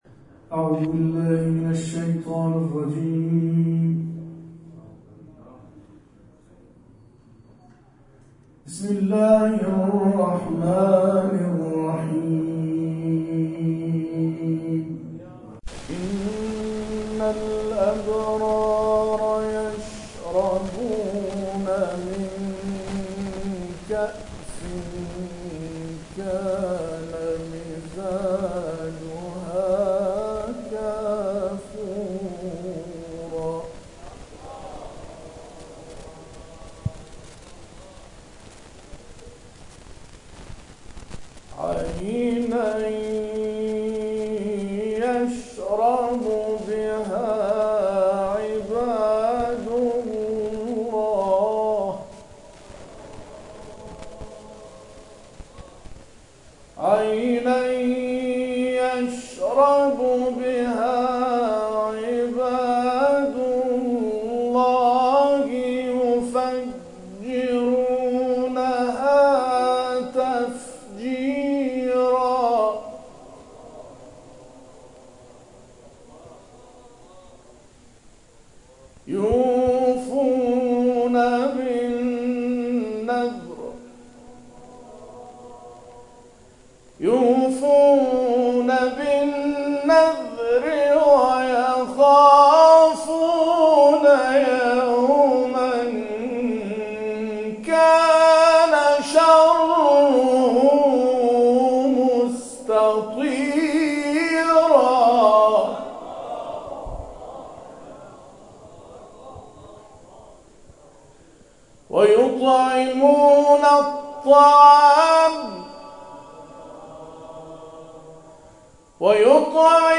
جشن قرآنی میلاد حضرت علی(ع)+ صوت وعکس
در ادامه تلاوت‌های این مراسم ارائه می‌شود.